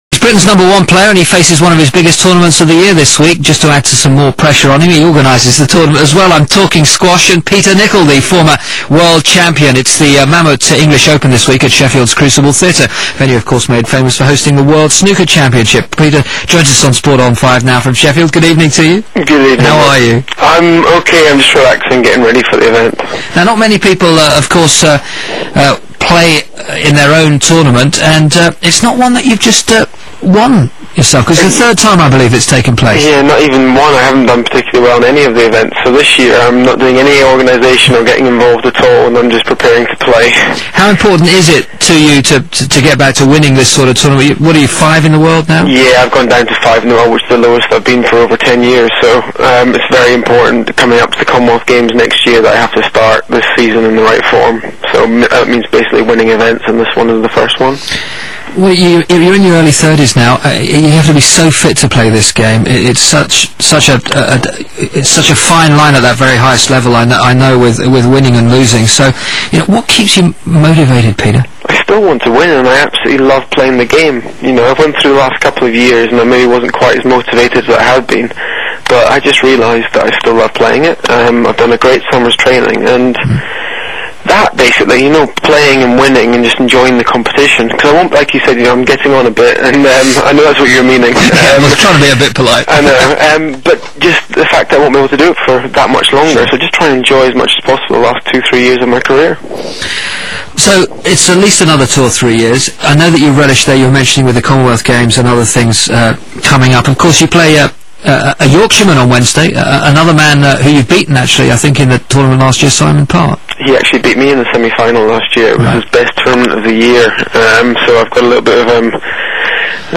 'Sport on 5', interview with Peter Nicol ...